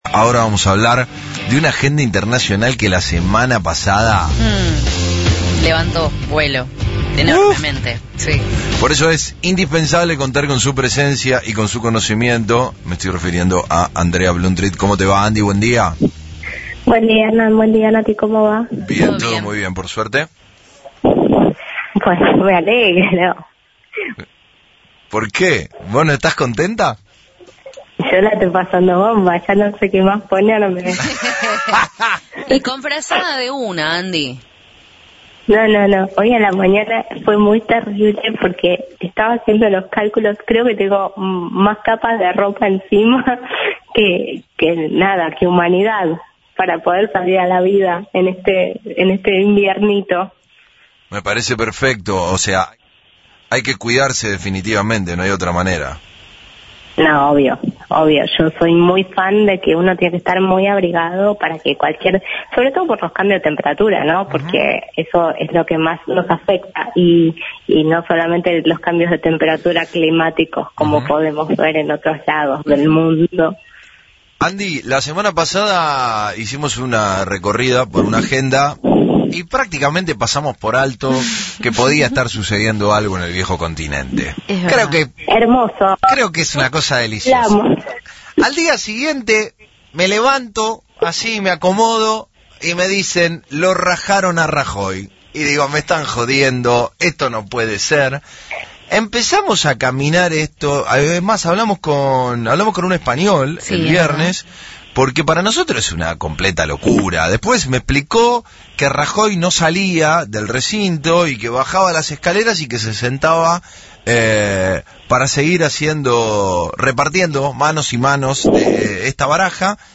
En diálogo con FRECUENCIA ZERO consideró «preocupante» el paso entre un partido de centroderecha hacia uno socialista por ser «dos extremos» y por la diferencia en el Congreso que tiene sólo 84 de 350 escaños.